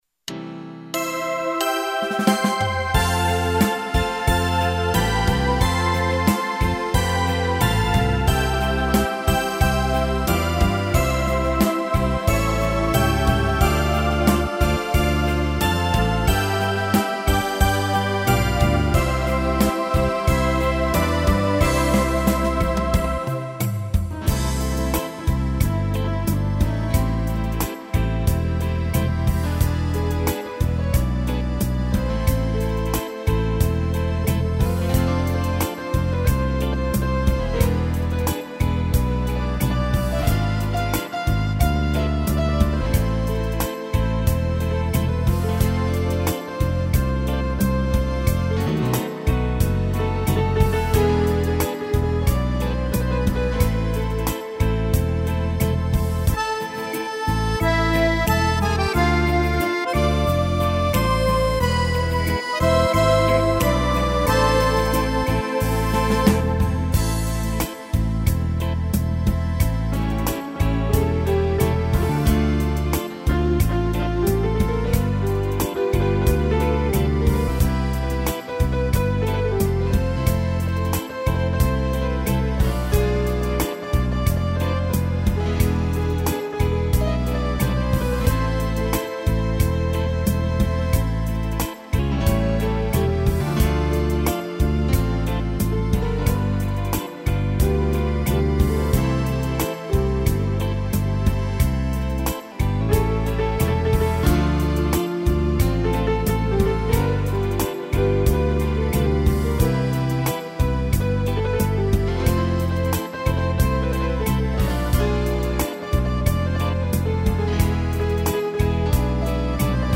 arranjo e interpretação teclado